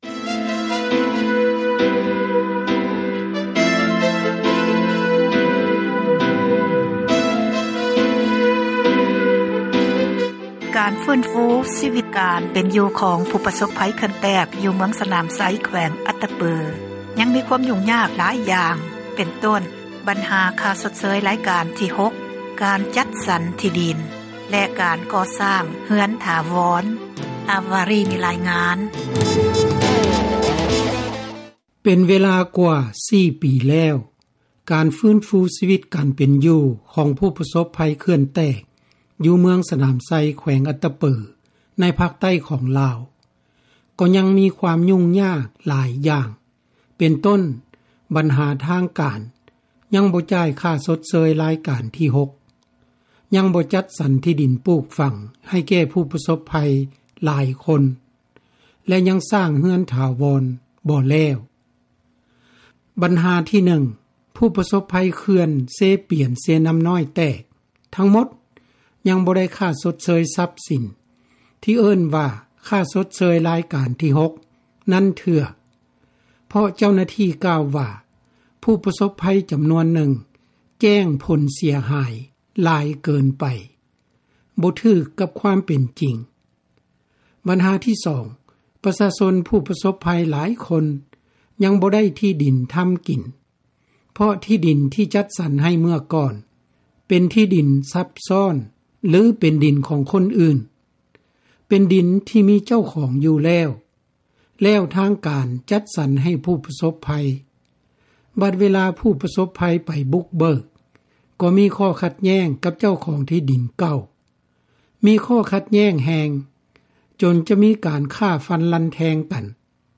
ຜູ້ປະສົບໄພຄົນນີ້ ກ່າວກັບວິທະຍຸ ເອເຊັຽ ເສຣີຕື່ມວ່າ: